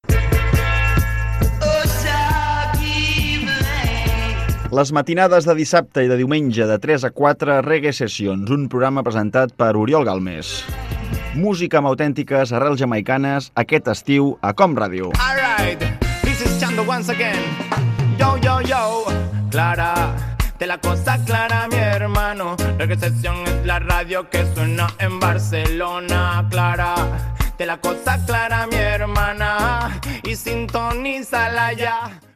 Promoció del programa de músiques d'arrels jamaicanes